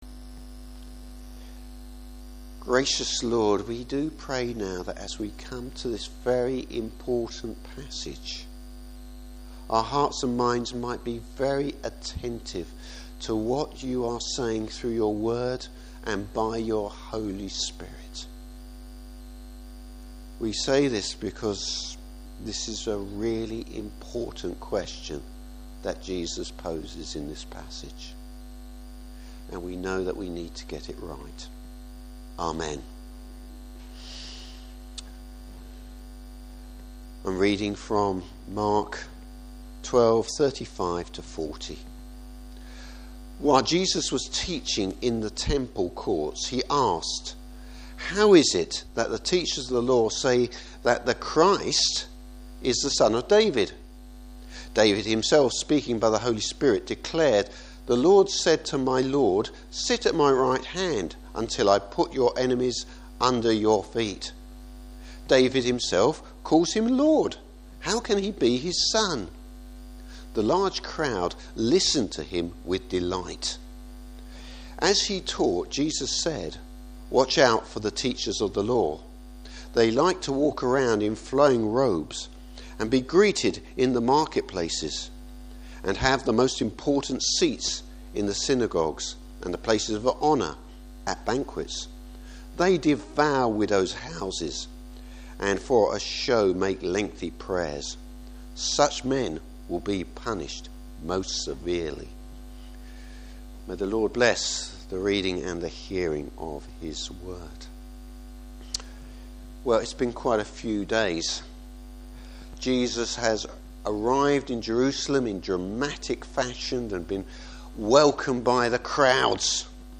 Service Type: Morning Service Who is the Christ?